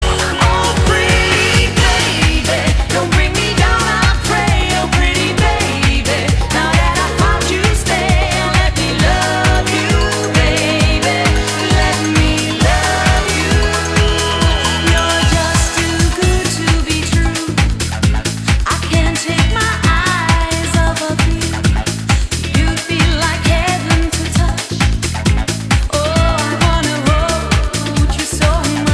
Besetzung: Blasorchester
Tonart: F-Dur